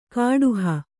♪ kāḍuha